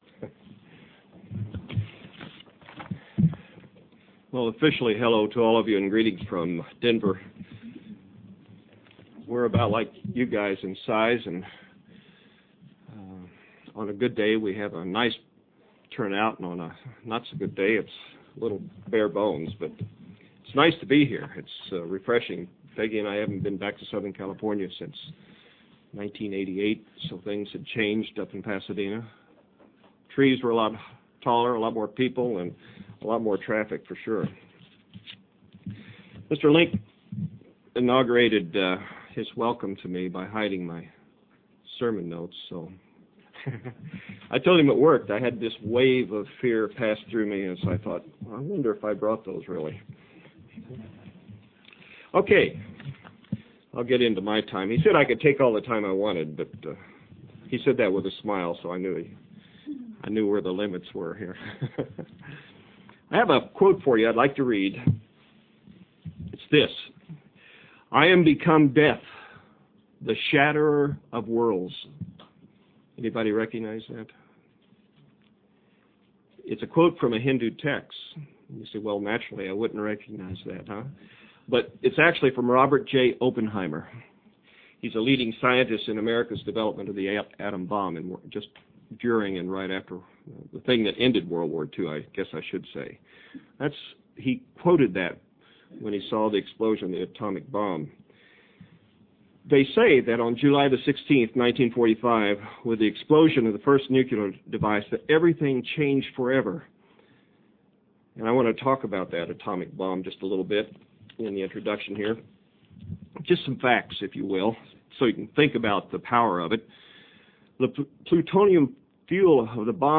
Sermons – Page 249 – Church of the Eternal God